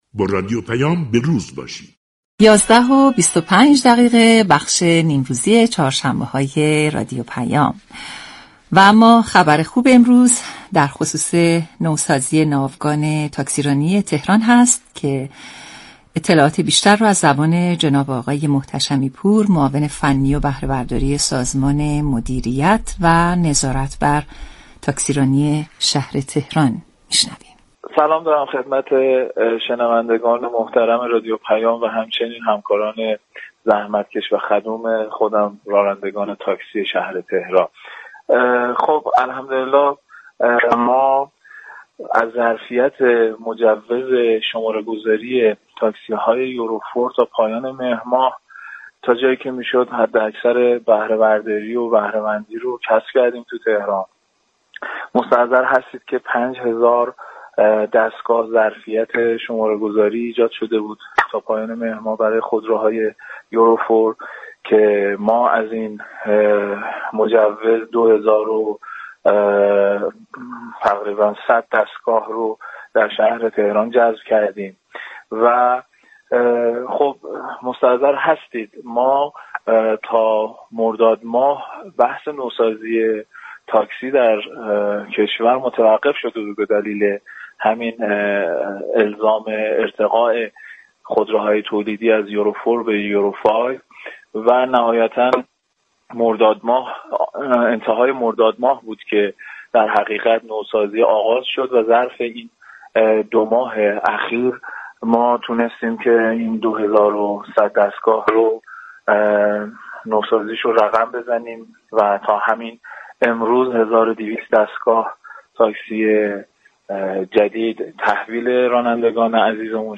در گفتگو با رادیو پیام از آغاز طرح نوسازی تاكسی‌های فرسوده خبر داد.